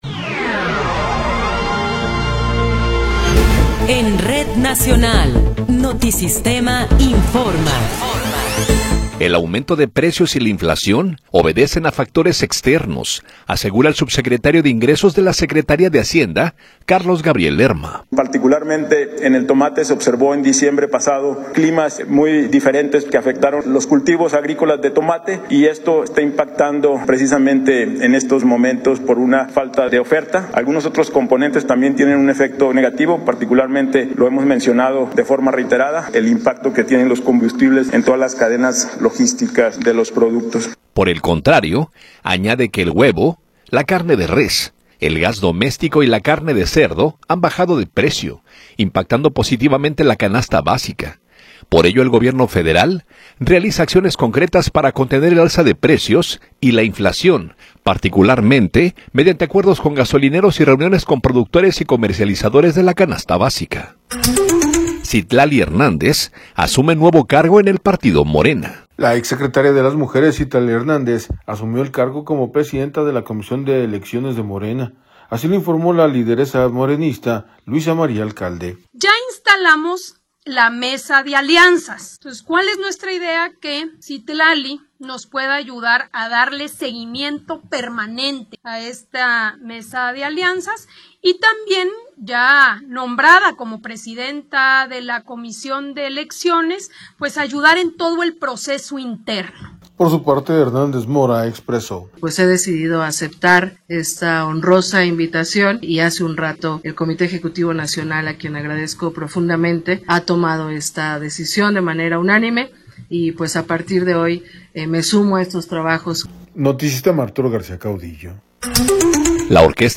Noticiero 15 hrs. – 16 de Abril de 2026
Resumen informativo Notisistema, la mejor y más completa información cada hora en la hora.